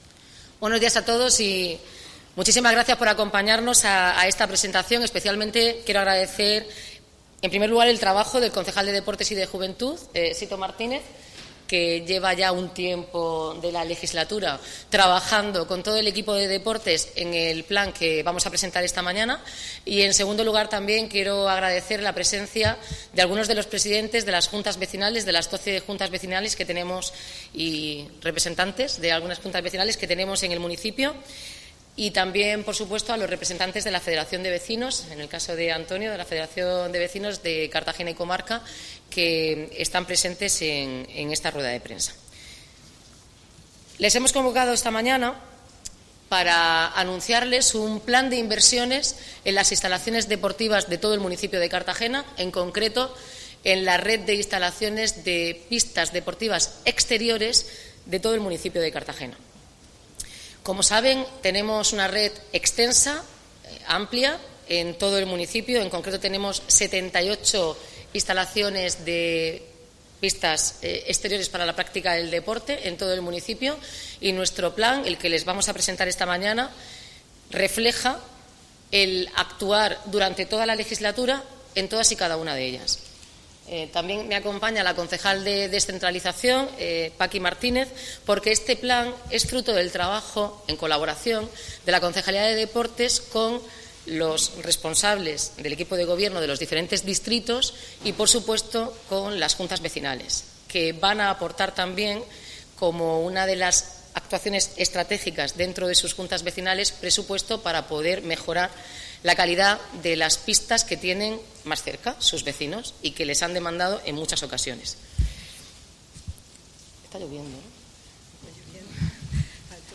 Enlace a Declaraciones de la alcaldesa, Noelia Arroyo.